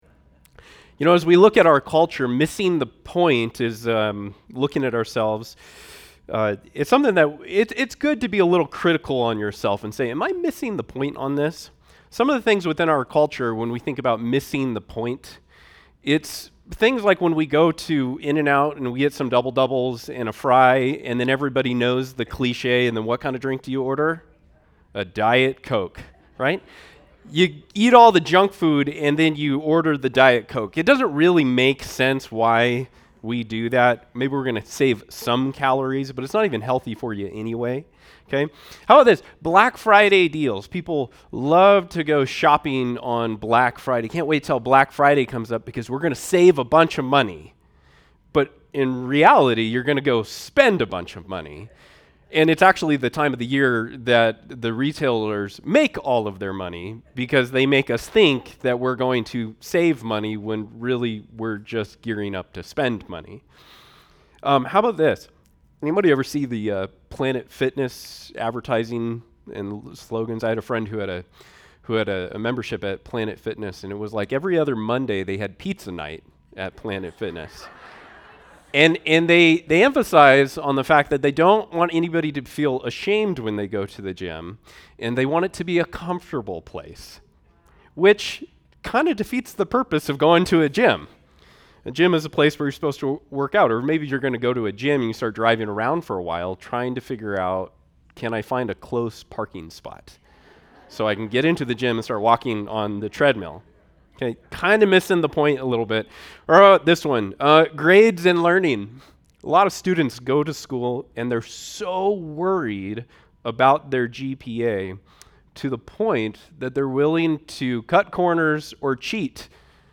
Message: “Tradition”